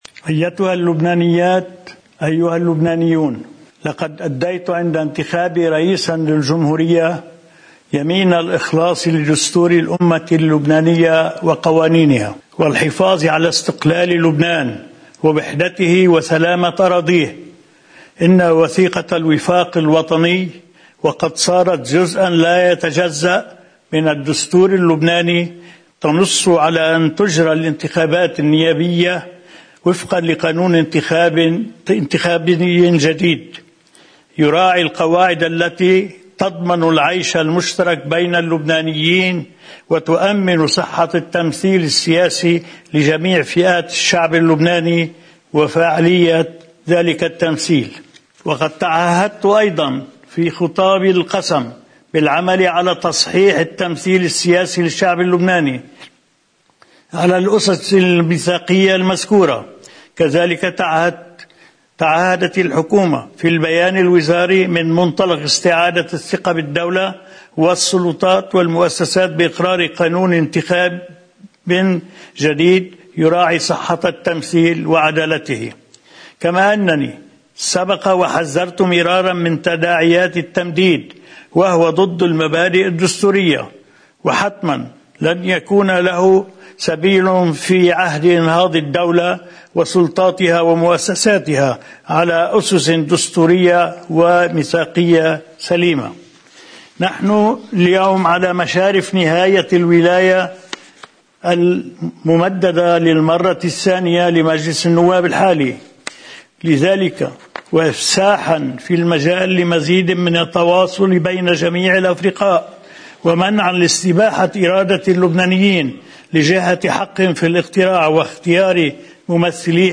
توجّه الرئيس ميشال عون بكلمة الى اللبنانيين قائلا: